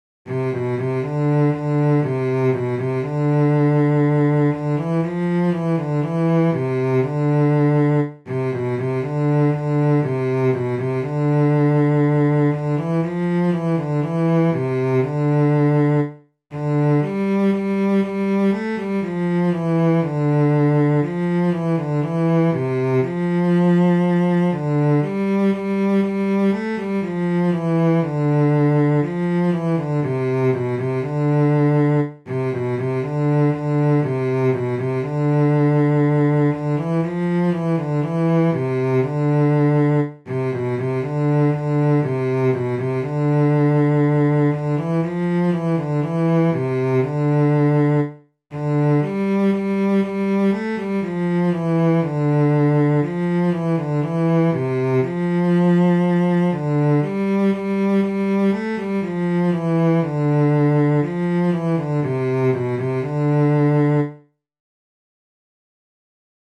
Jewish Folk Song (Zusha & Pumbadisa melody)
D minor ♩= 120 bpm